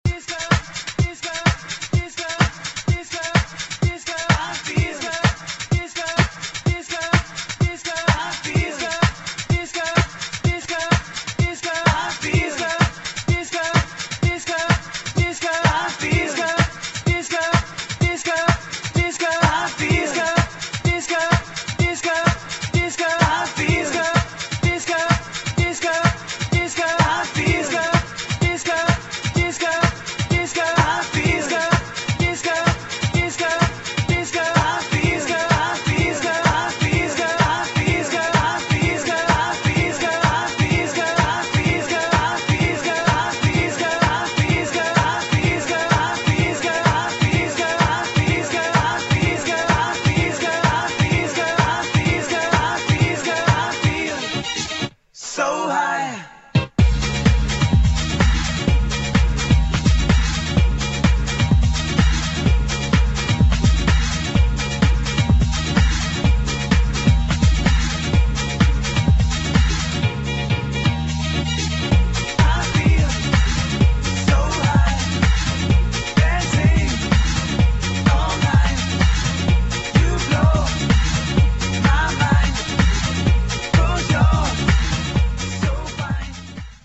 [ FRENCH HOUSE ]